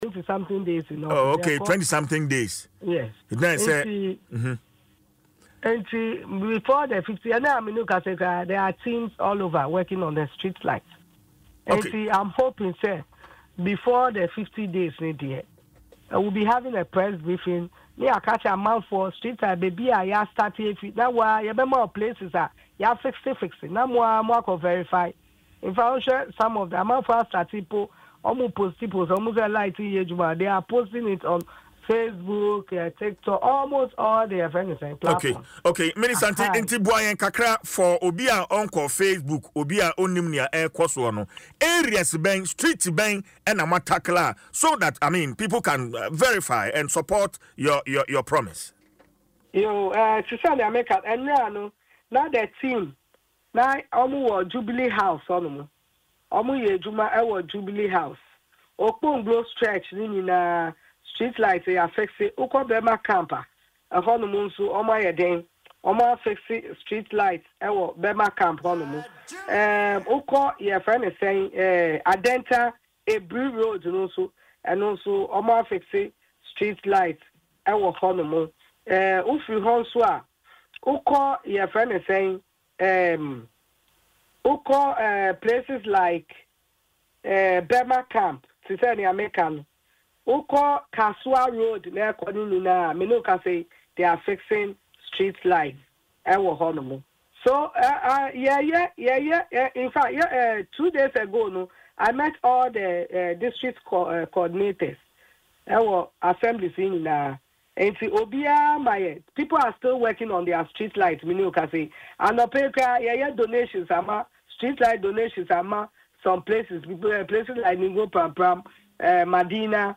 Speaking on Adom FM’s morning show, Dwaso Nsem, she explained that while the government is committed to improving street lighting, vandalism remains a major challenge.